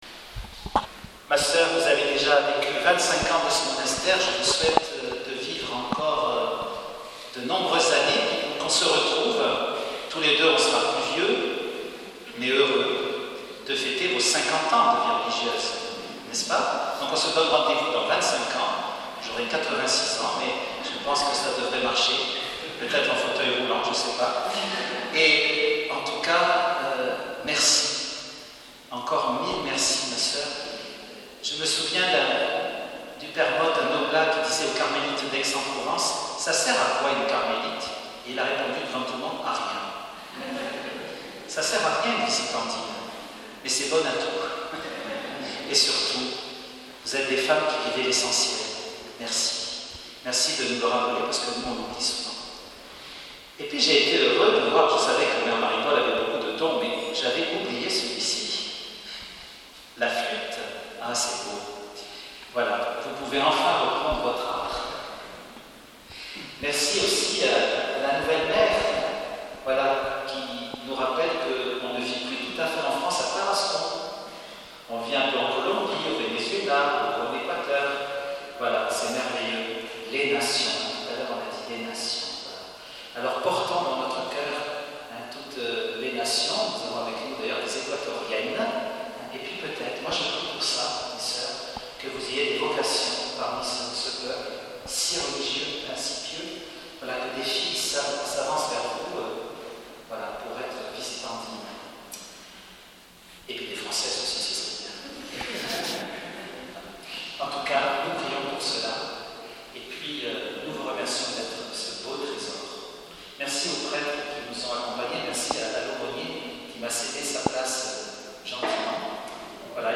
Encore un petit mot à la fin de la célébration :